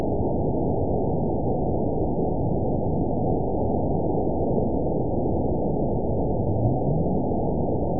event 922735 date 03/24/25 time 05:29:29 GMT (2 months, 3 weeks ago) score 9.43 location TSS-AB10 detected by nrw target species NRW annotations +NRW Spectrogram: Frequency (kHz) vs. Time (s) audio not available .wav